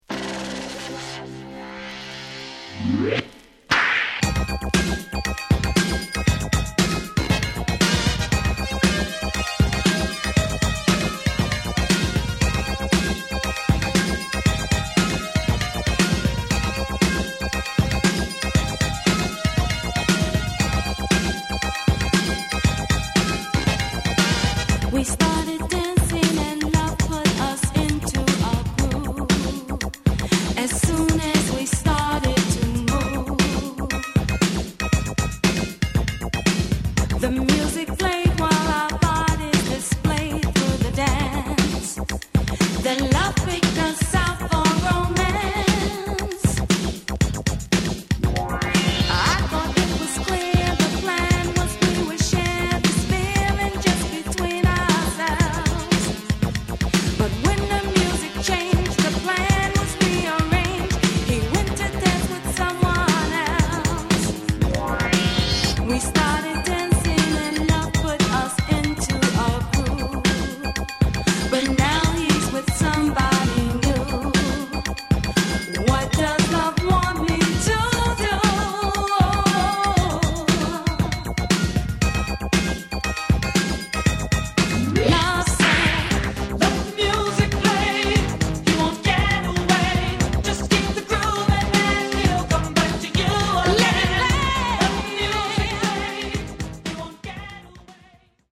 essential post-disco classic from 1983
It has Mint labels and pristine sound.